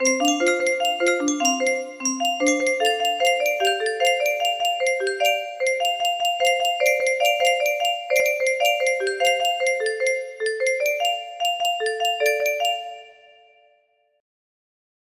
N's Lullaby music box melody